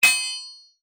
Sword Hit A.wav